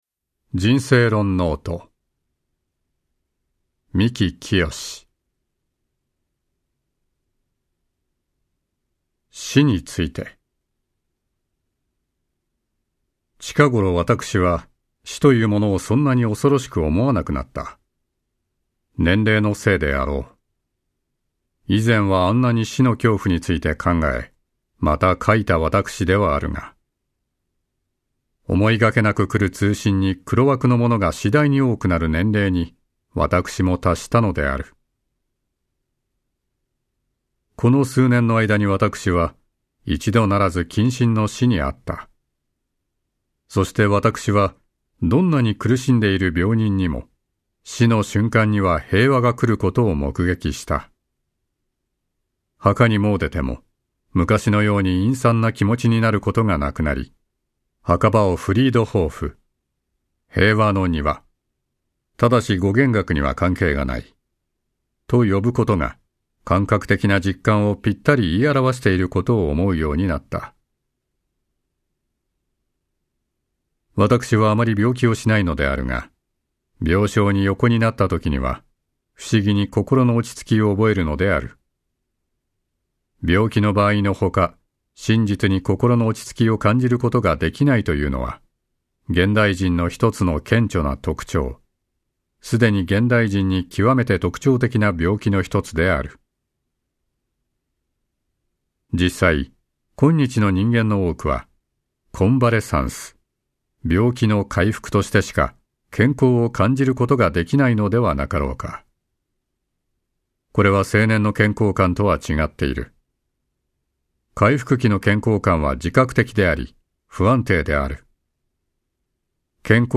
[オーディオブック] 人生論ノート